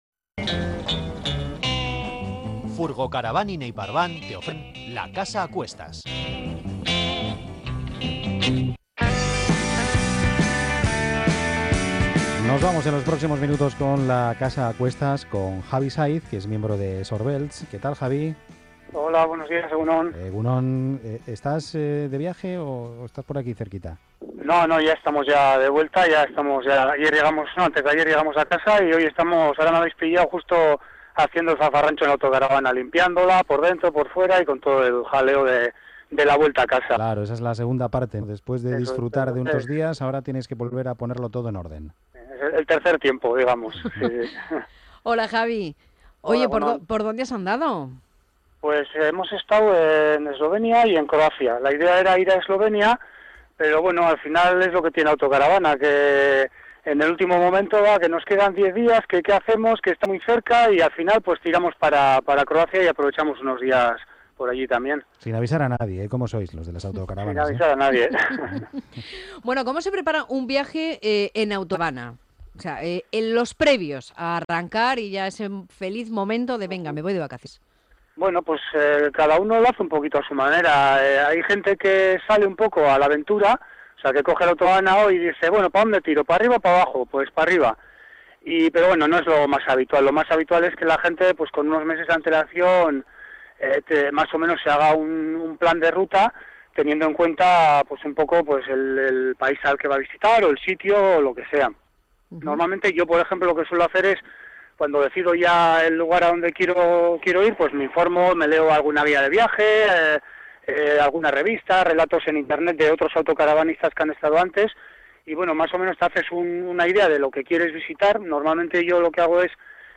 En los siguientes enlaces podéis escuchar varias entrevistas a miembros del club, que se han emitido en el pasado mes de agosto en el programa “Con la casa a cuestas” de Onda Vasca: